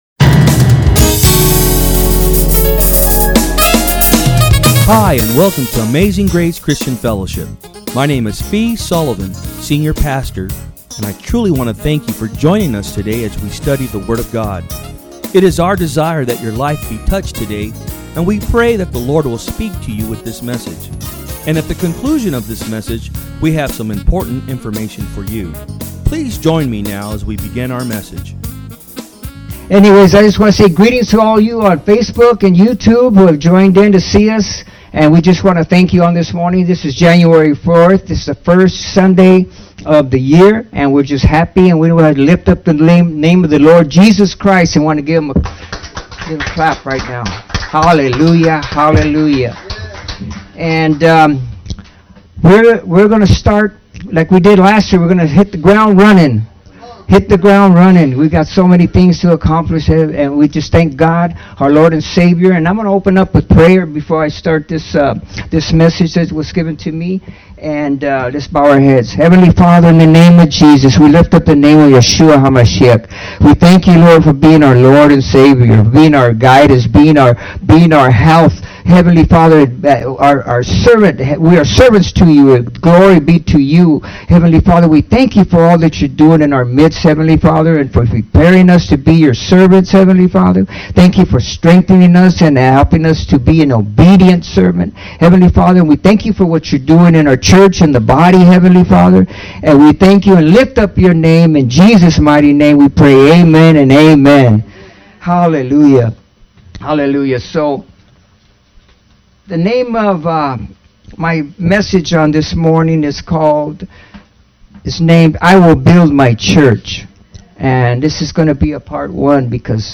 Sermons
From Service: "Sunday Am"